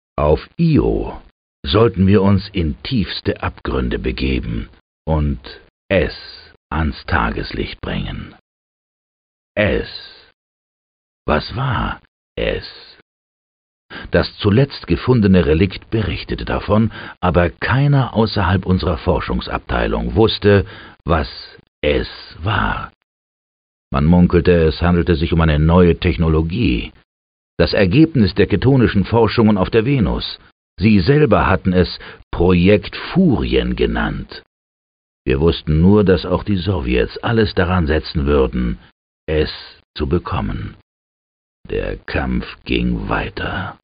Jack Nicholson appears as the bitter narrator who mysteriously prepares the player for each of the missions, and Clint Eastwood is the American general who always has a frustrating comment to offer whenever the player disregards his superior's orders.
Erzähler: